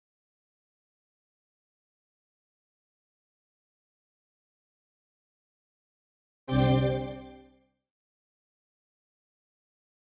Chrome spielt alle paar Minuten komischen Ton ab
Hi, Chrome spielt bei mir alle paar Minuten einen komischen Ton ab, den ich angehängt habe.